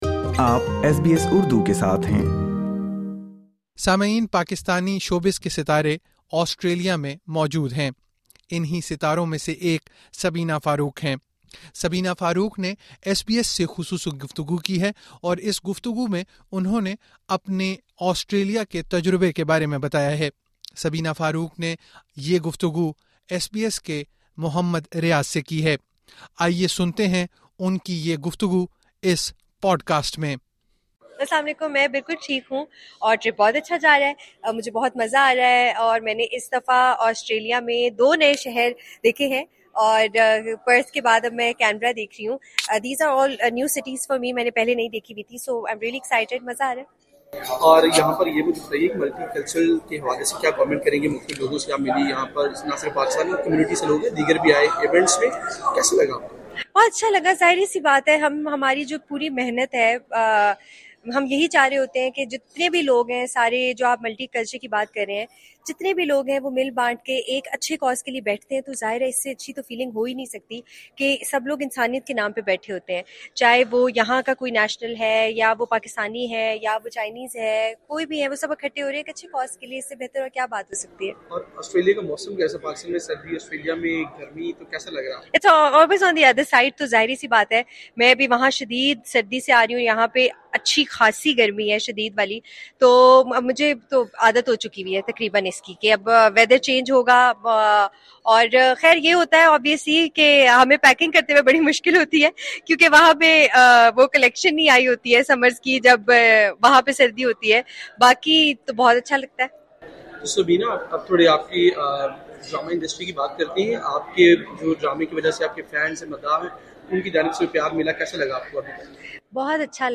سبینا فاروق نے ایس بی ایس اردو سے خصوصی گفتگو میں کہا کہ آسٹریلیا میں مداحوں کی محبت اور پذیرائی نے ان کے دورے کو یادگار بنا دیا ہے۔